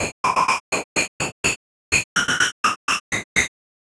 Synthetic 02.wav